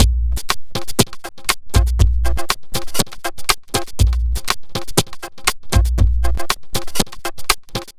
Astro 4 Drumz.wav